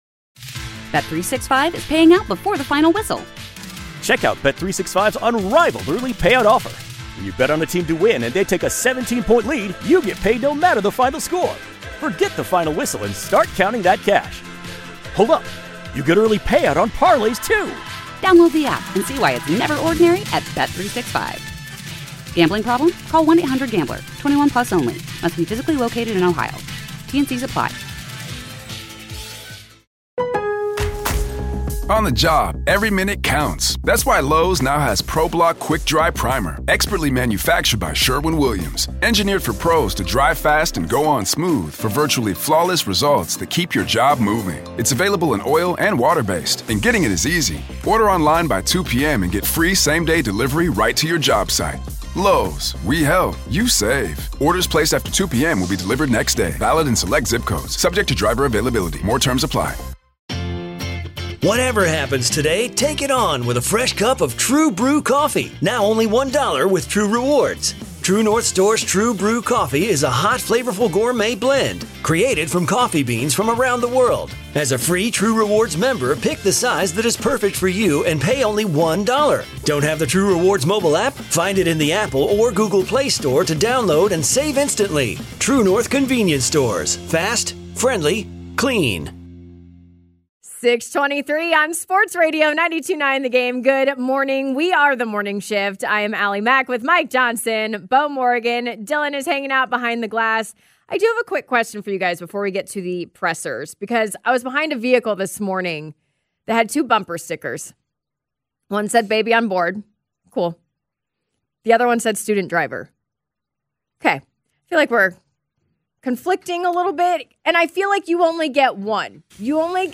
let you hear Atlanta Falcons quarterback Michael Penix Jr. talk about the people who help him outside of the Falcons building